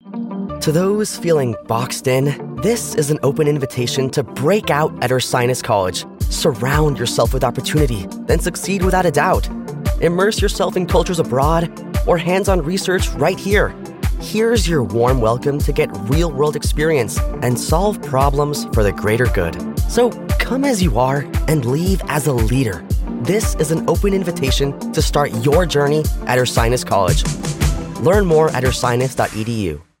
College and Universities